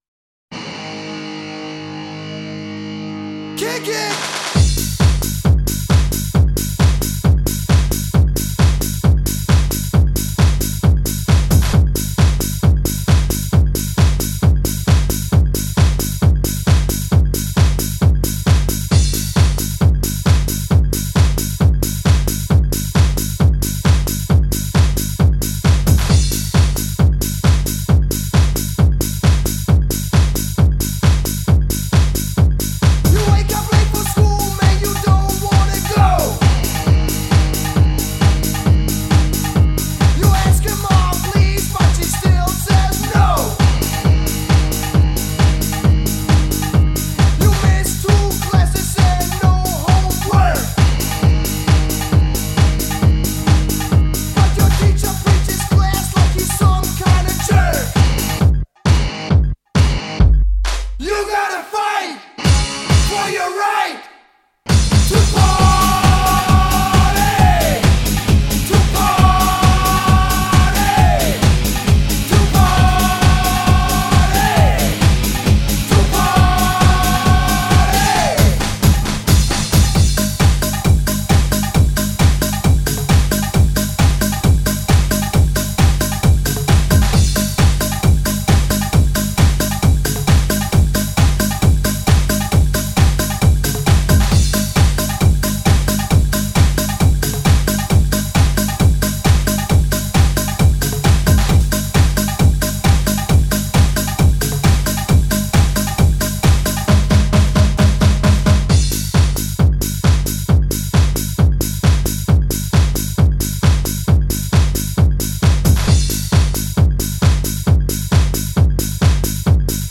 Жанр: Club-House